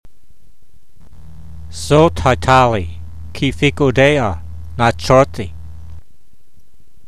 v56_voice.mp3